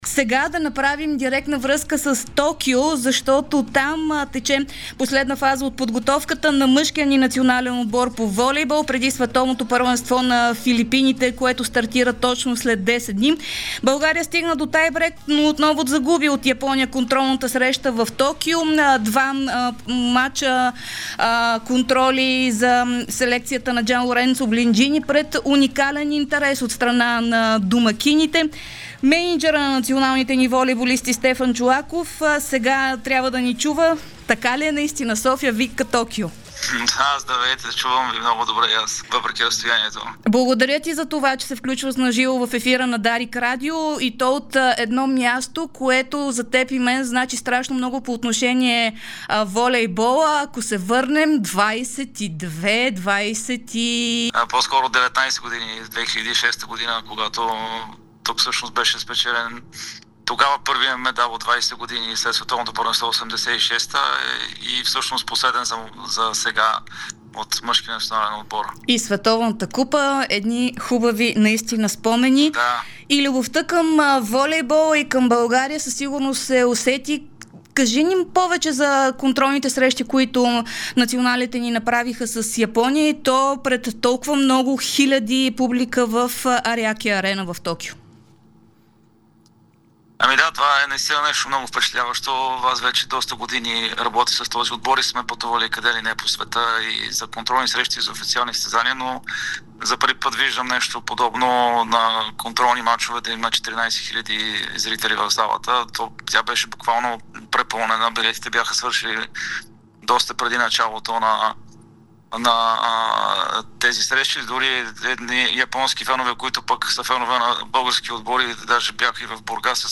даде ексклузивно интервю пред Дарик радио и Dsport, в което коментира много теми, свързани с отбора.